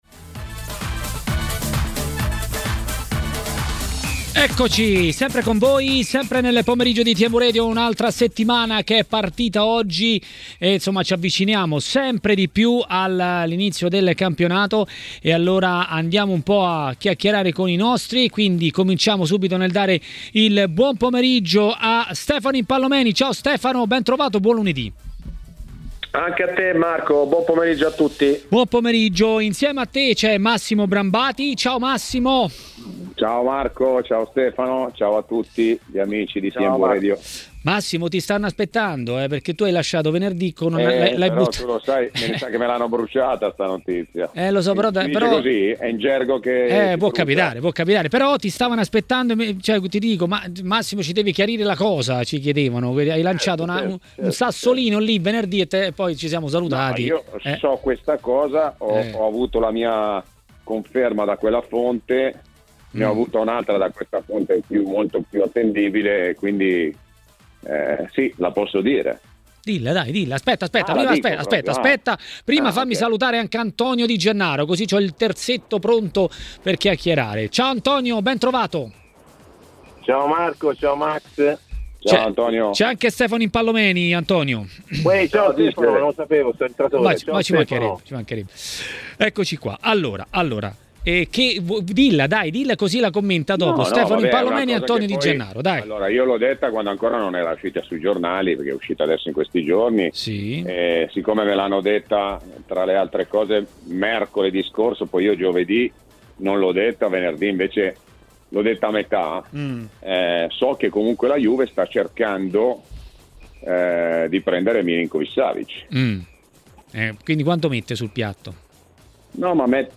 Sulle trattative di calciomercato è intervenuto a Maracanà, nel pomeriggio di TMW Radio, l'ex calciatore Antonio Di Gennaro.